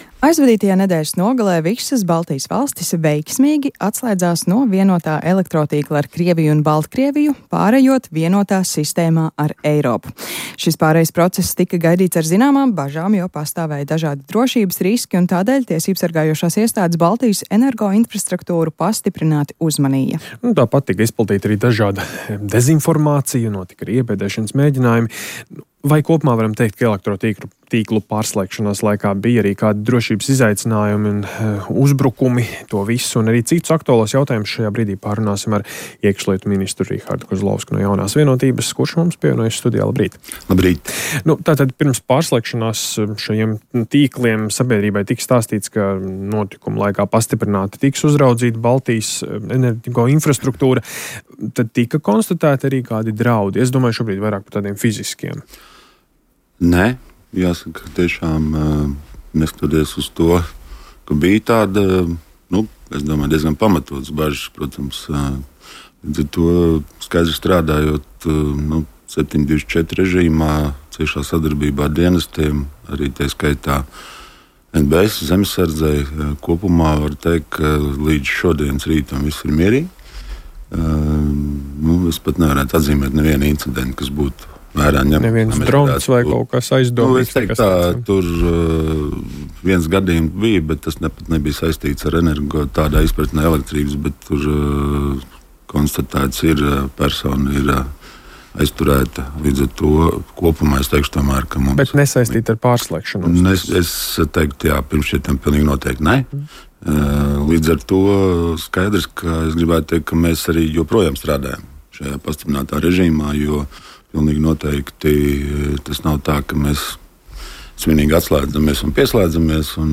Baltijai pārejot vienotā energosistēmā ar Eiropu, bija pamatotas aizdomas par iespējamiem draudiem, tie tomēr nav notikuši. Tā intervijā Latvijas Radio sacīja iekšlietu ministrs Rihards Kozlovskis no "Jaunās Vienotības".